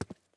ES_Footsteps Concrete 7.wav